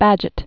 (băjət), Walter 1826-1877.